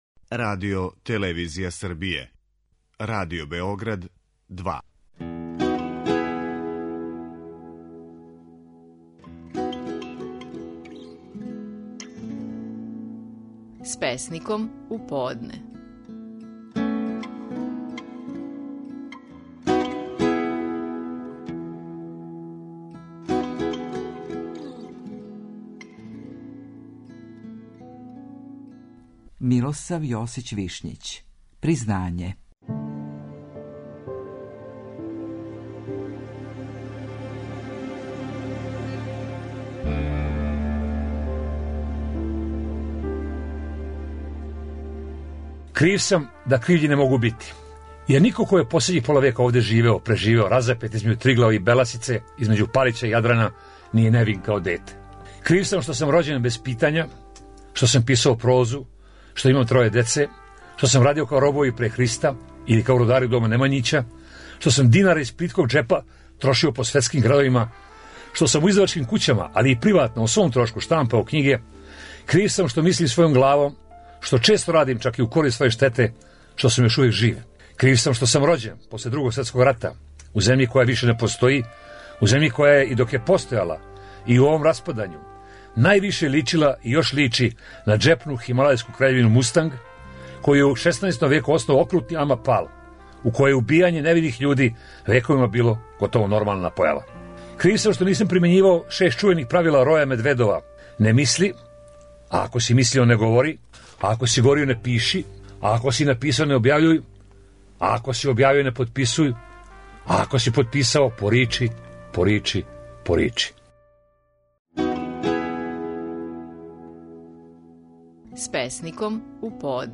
Стихови наших најпознатијих песника, у интерпретацији аутора.
Милосав Јосић Вишњић говори своју песму „Признање".